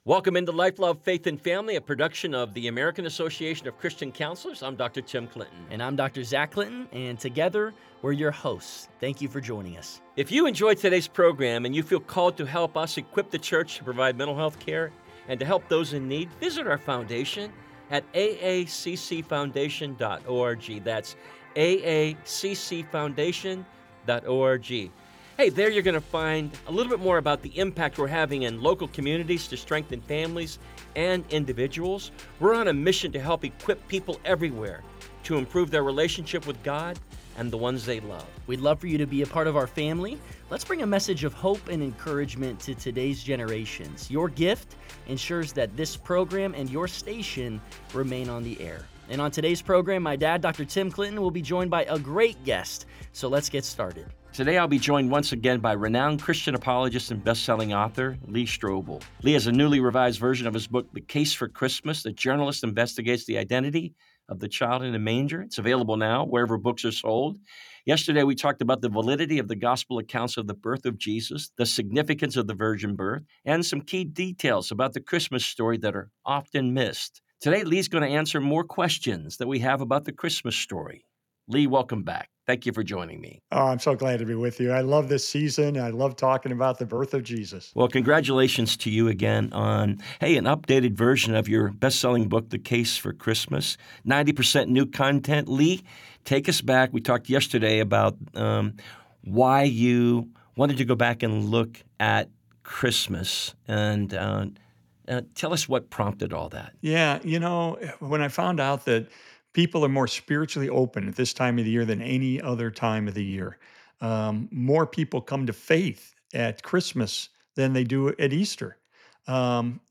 is joined again by renowned apologist Lee Strobel as they continue their conversation about The Case for Christmas . Lee dives into big questions surrounding the Christmas story, from the historical background of the real St. Nicholas to the wise men, the star of Bethlehem, and more.